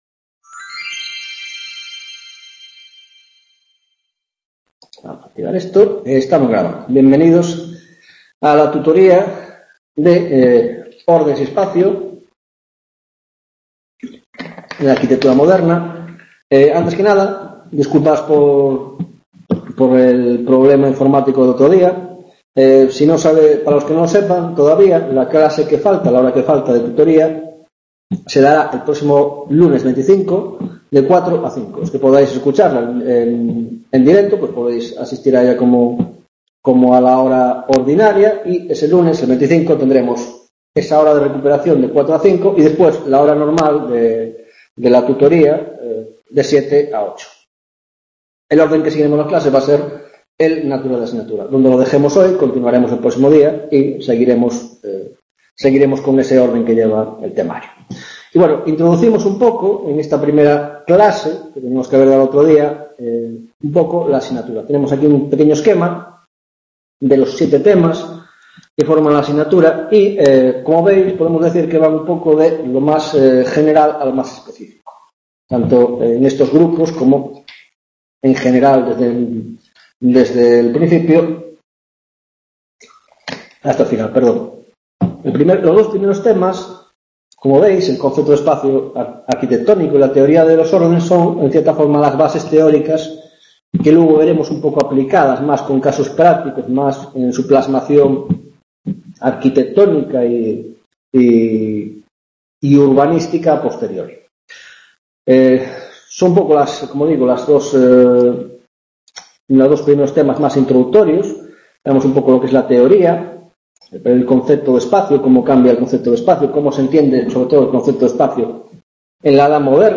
1ª Tutoría de Órdenes y Espacio en la Arquitectura del XV - XVIII - Introducción: La Concepción del Espacio